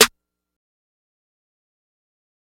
TS - SNARE (4).wav